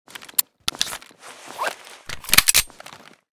fn57_reload_empty.ogg.bak